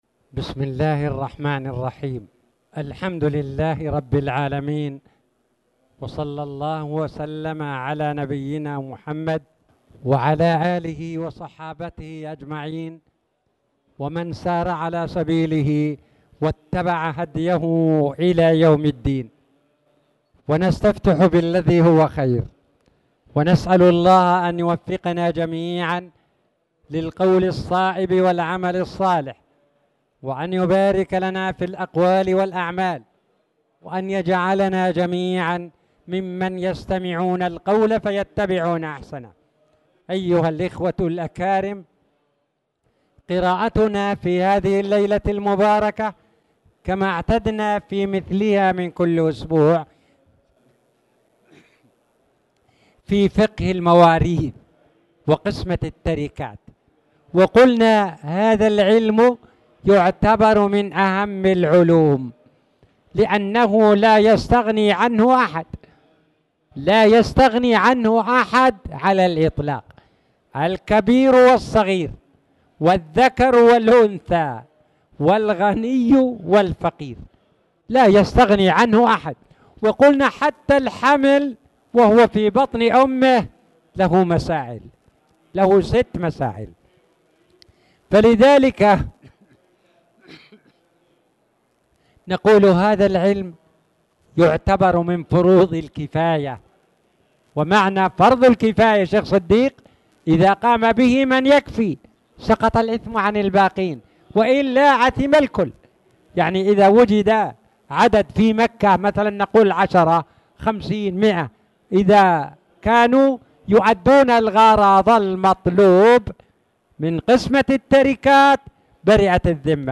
تاريخ النشر ٢٥ ربيع الثاني ١٤٣٨ هـ المكان: المسجد الحرام الشيخ